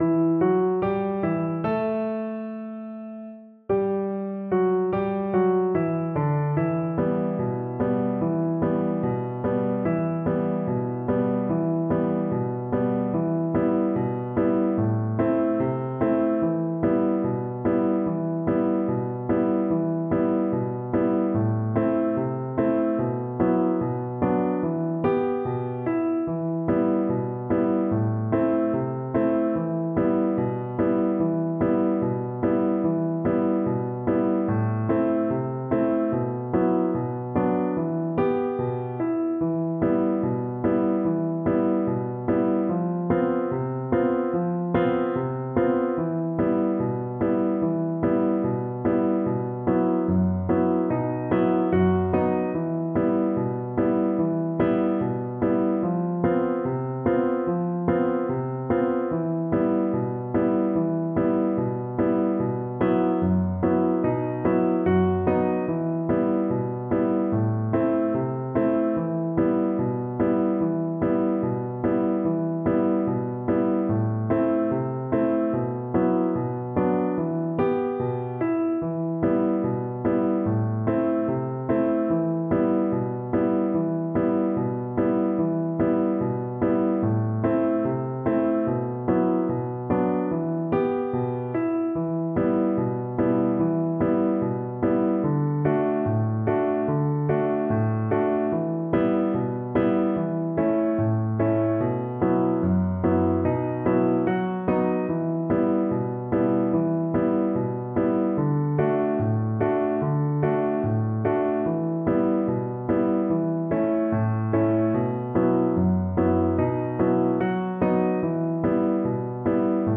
Violin
E minor (Sounding Pitch) (View more E minor Music for Violin )
4/4 (View more 4/4 Music)
Traditional (View more Traditional Violin Music)